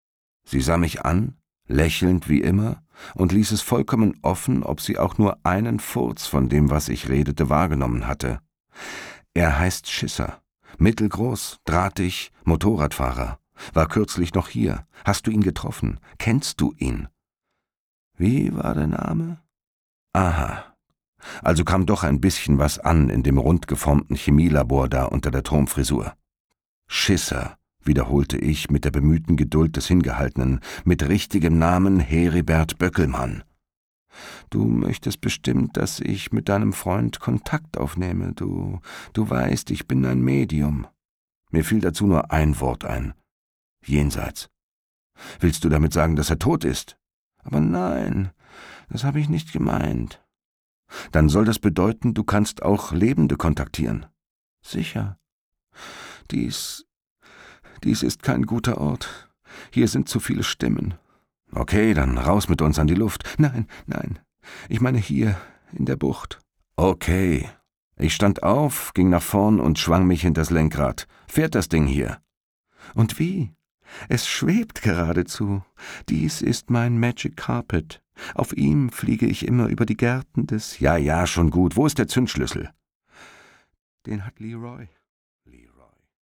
Doku